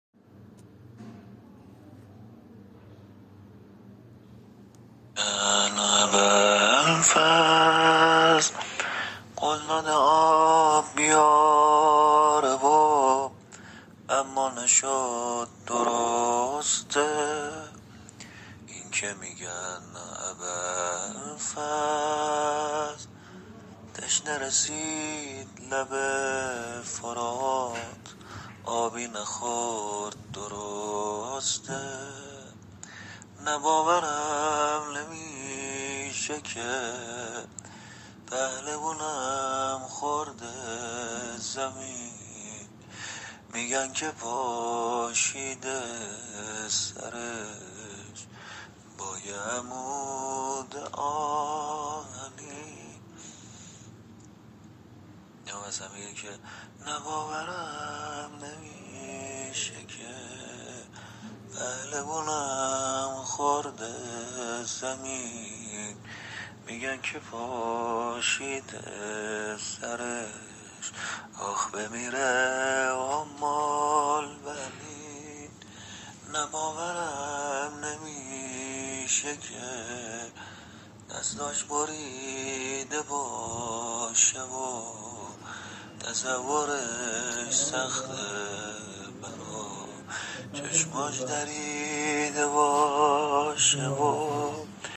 زمزمه حضرت ام البنین سلام الله علیها -(اینکه میگن ابالفضل قول داده آب بیاره و ، اما نشد درسته ؟